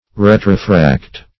retrofract - definition of retrofract - synonyms, pronunciation, spelling from Free Dictionary
Search Result for " retrofract" : The Collaborative International Dictionary of English v.0.48: Retrofract \Re"tro*fract\, Retrofracted \Re"tro*fract`ed\, a. [Pref. retro- + L. fractus, p. p. of frangere to break.]